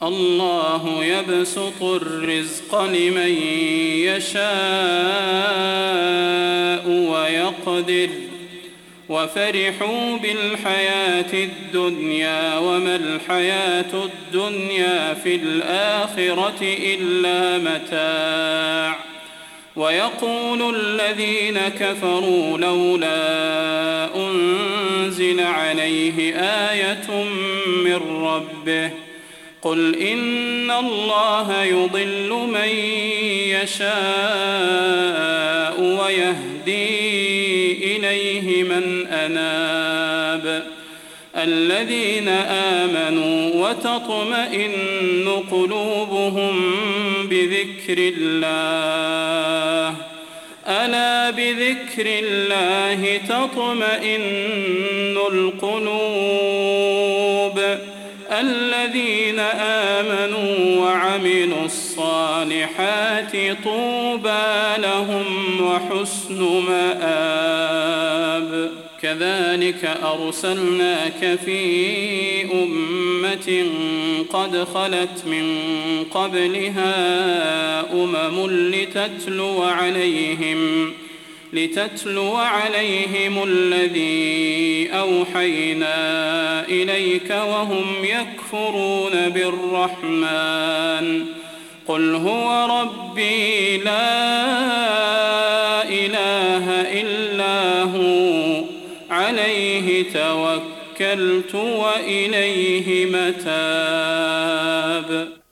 فروض مغرب البدير 1420